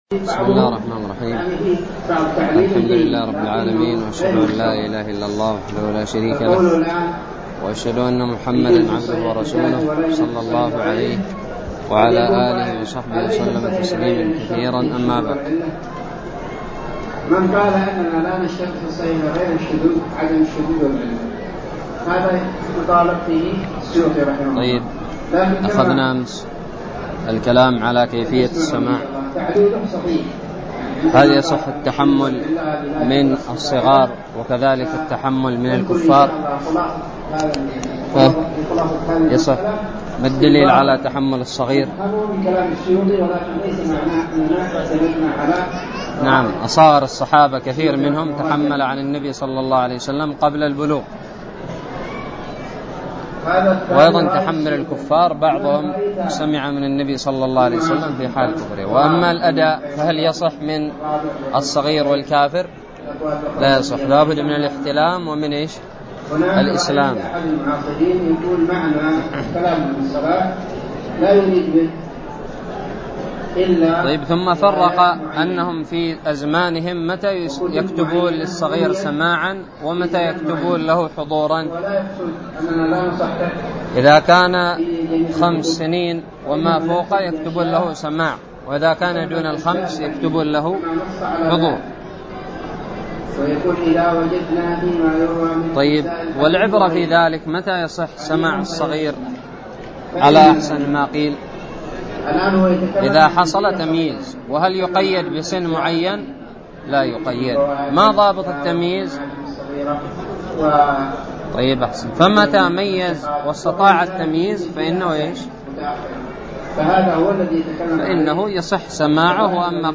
الدرس الثاني والثلاثون من شرح كتاب الباعث الحثيث
ألقيت بدار الحديث السلفية للعلوم الشرعية بالضالع